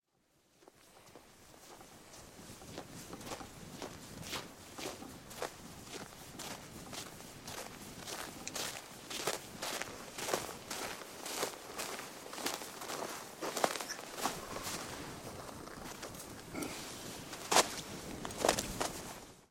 努纳维克 " 脚步声 靴子 雪地上的接近，干净利落的漂亮
描述：脚步靴雪的方法关闭干净清爽干净